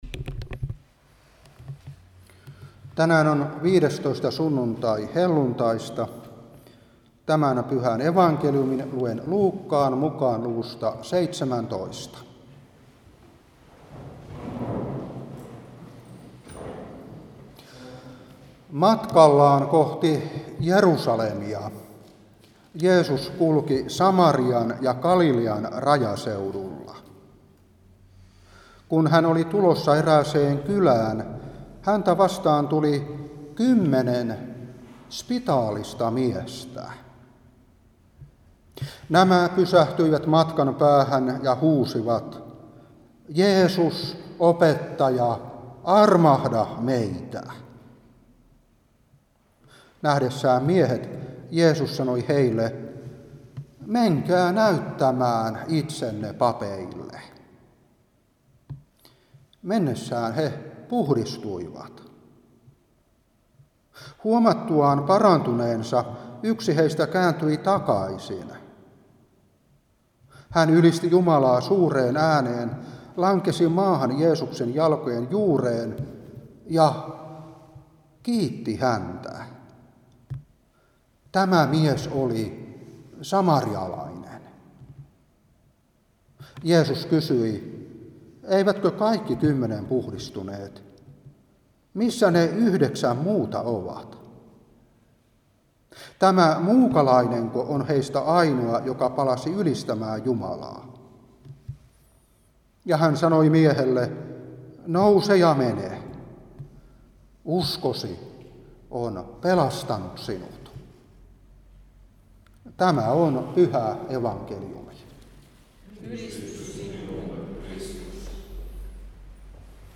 Saarna 2022-9.